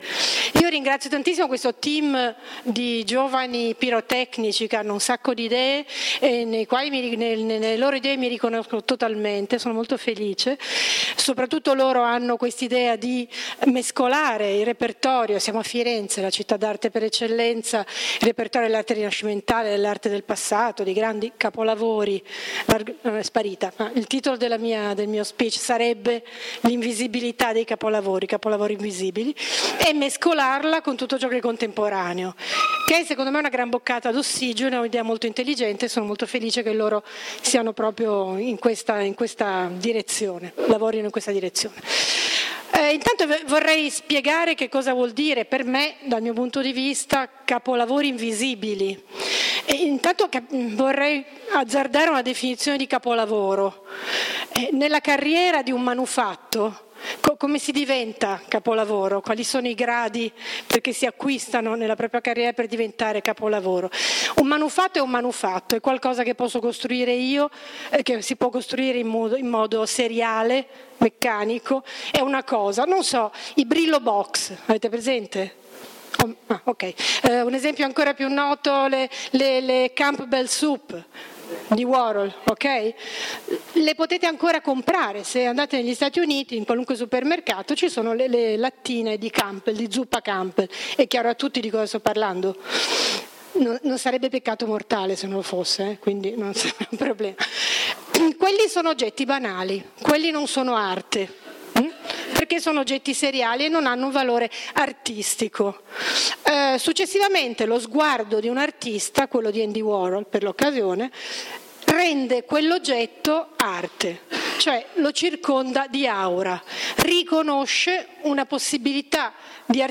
LECTURE / Invisible Masterpieces